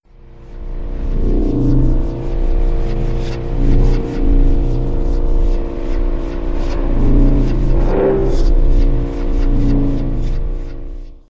ScifiShip.wav